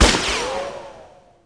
pistol_fire_ulti_01.wav